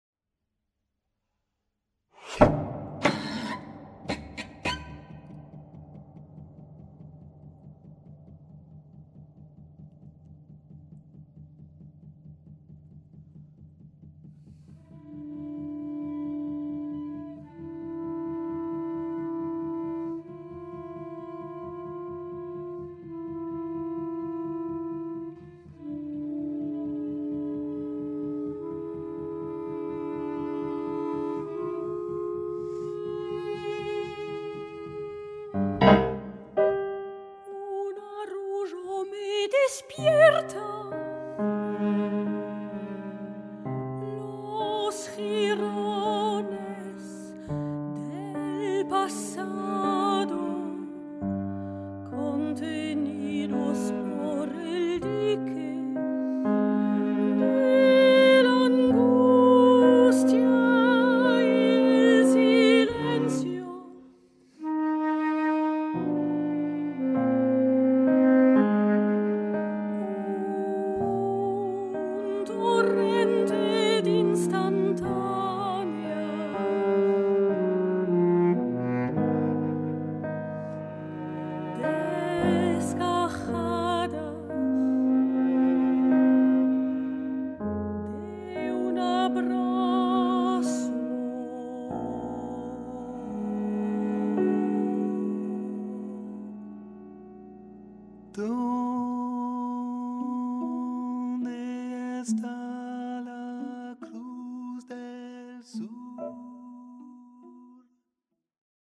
chant (soprano)
saxophone(s)
piano, chant, arrangement
alto, accordéon,arrangement